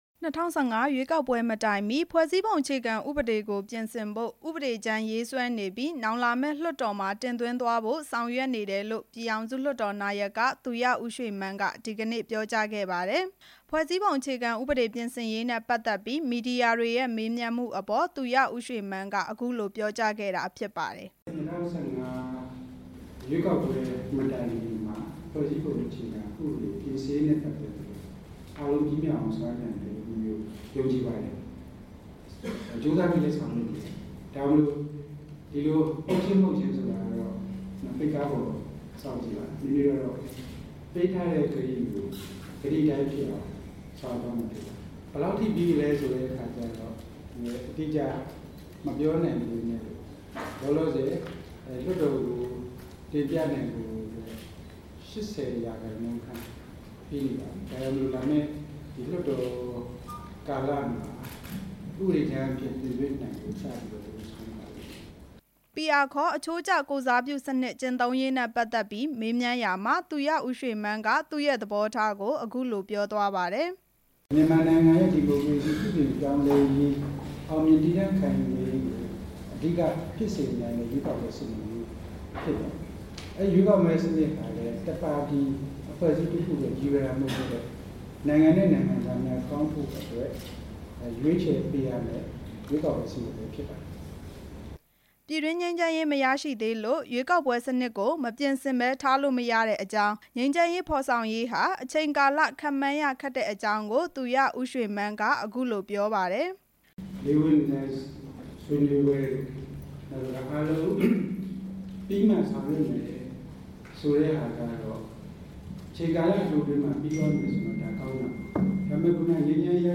ဒီကနေ့ ပြည်ထောင်စု လွှတ်တော်နာယက သူရဦးရွှေမန်းက ဇမ္မူသီရိခန်းမဆောင်မှာ သတင်းထောက်တွေကို ပြောခဲ့တာဖြစ်ပါတယ်။